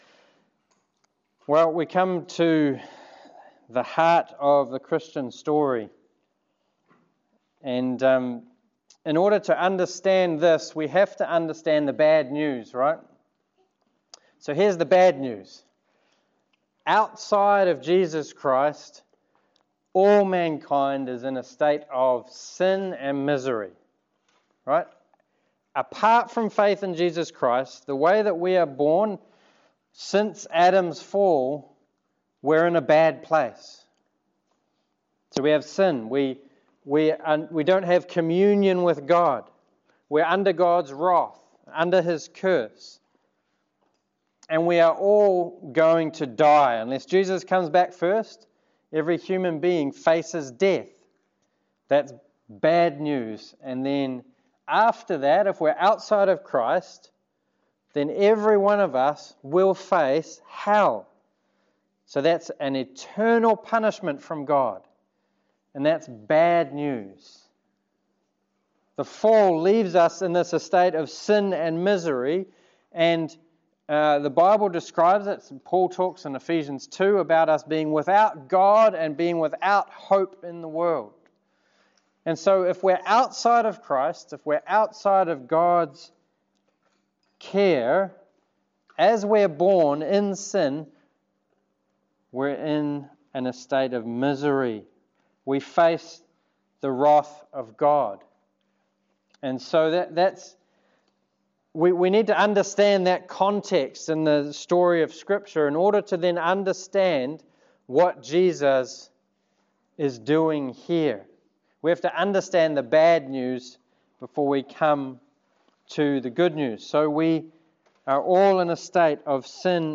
Mark 15:21-39 Service Type: Sermon Whether we admit it or not